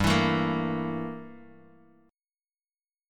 Gm6add9 Chord